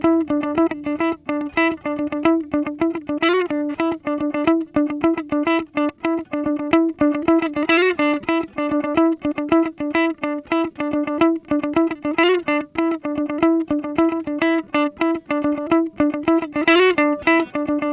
DF_107_E_FUNK_GTR_01.wav